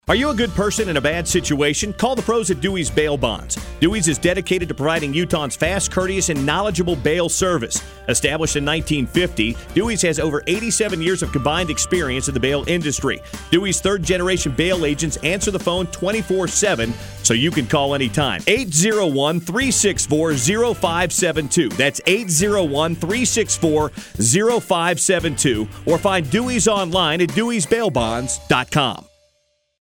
Our ad on ESPN700
ESPN700-audio-spot.mp3